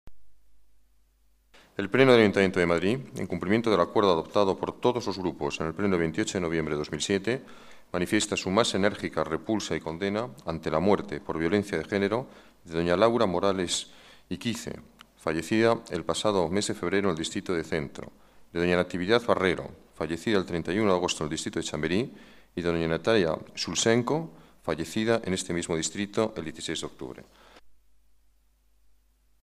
Nueva ventana:El alcalde da lectura a la declaración institucional contra la violencia de género